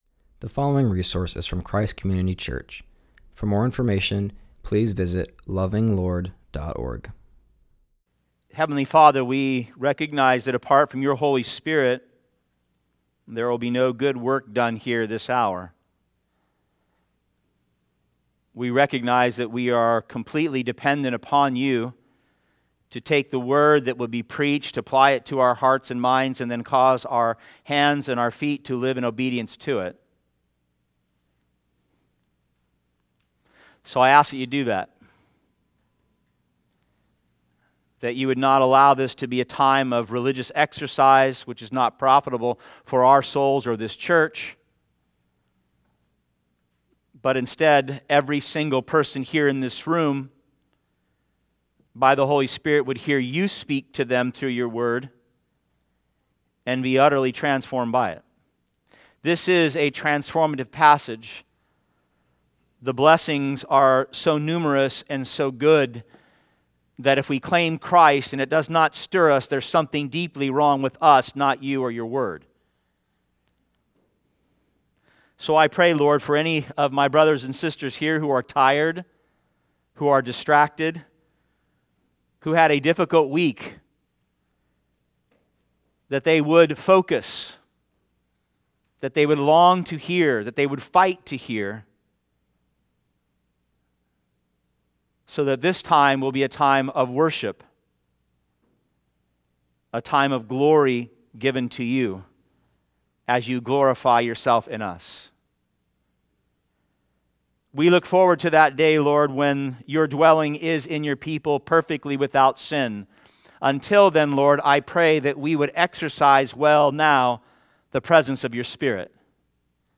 continues our series and preaches from Ephesians 2:17-22.